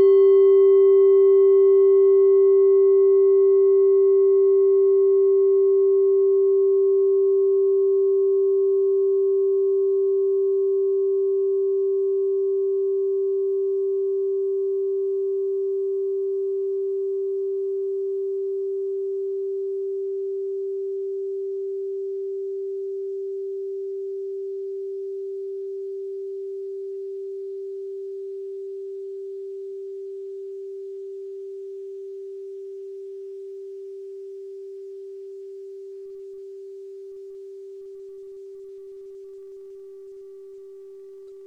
Kleine Klangschale Nr.29
Diese Klangschale ist eine Handarbeit aus Bengalen. Sie ist neu und ist gezielt nach altem 7-Metalle-Rezept in Handarbeit gezogen und gehämmert worden.
(Ermittelt mit dem Minifilzklöppel)
Auf unseren Tonleiter entspricht er etwa dem "G".
kleine-klangschale-29.wav